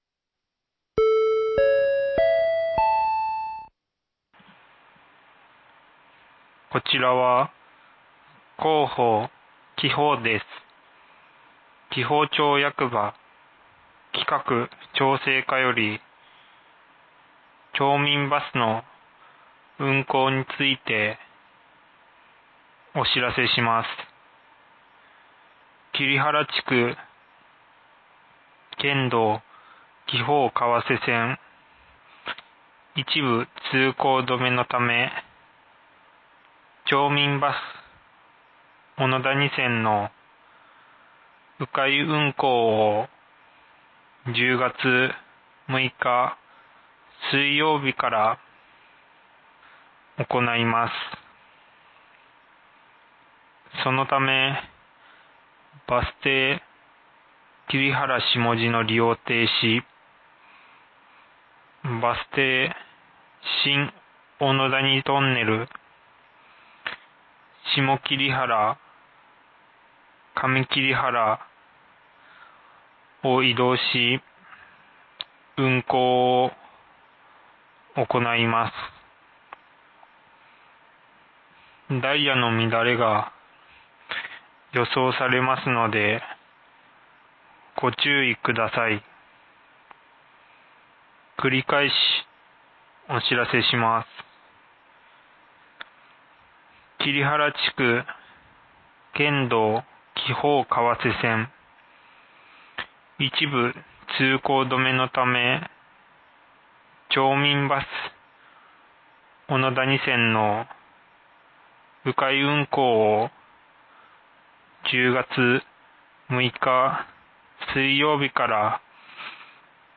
放送音声